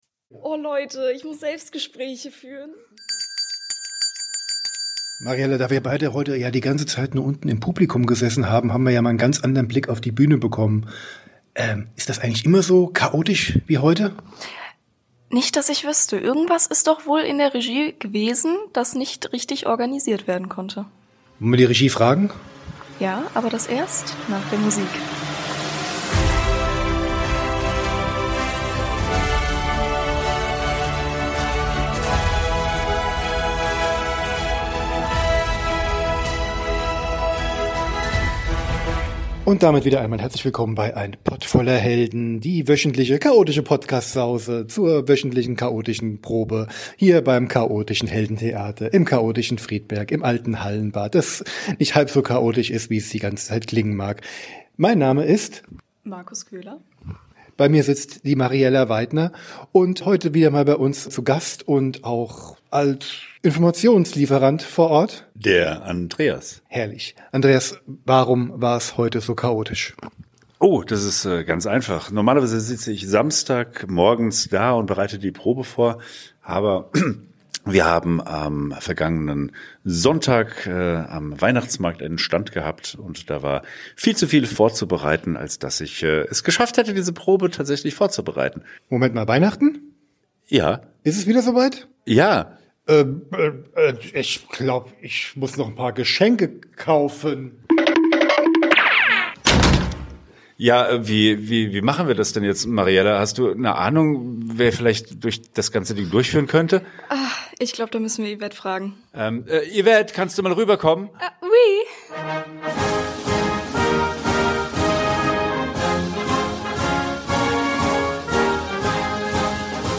Die Helden hatten bei Regen einen schweren... ich meine natürlich schönen Stand auf dem Weihnachtsmarkt in Friedberg.